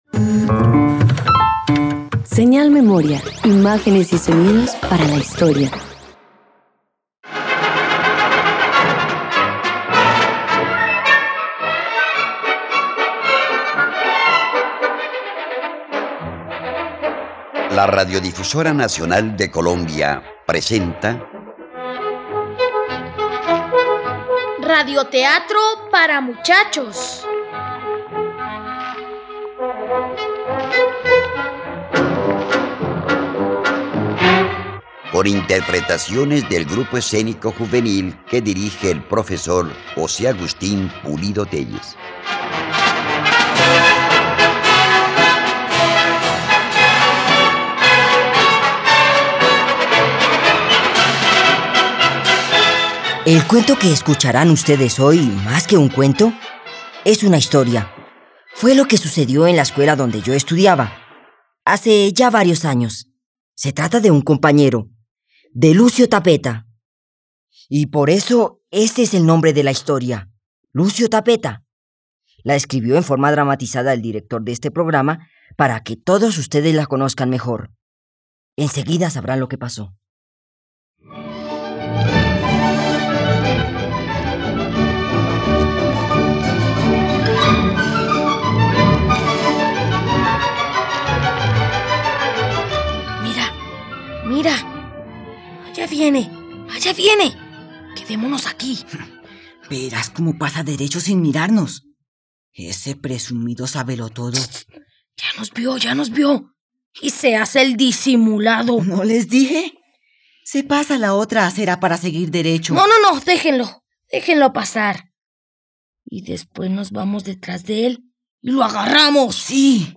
Lucio Tapeta - Radioteatro dominical | RTVCPlay